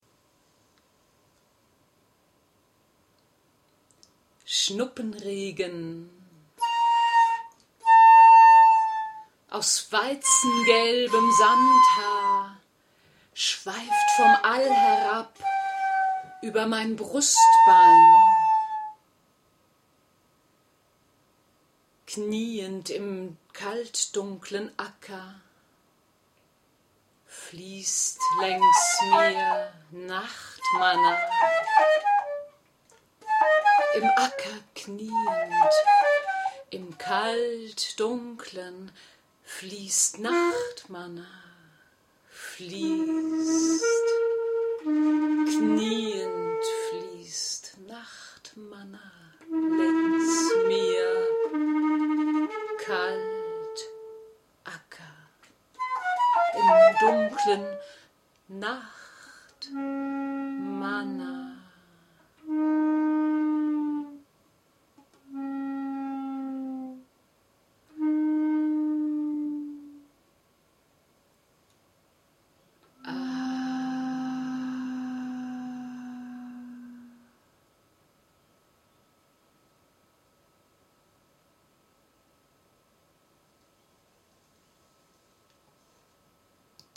flûte
guitare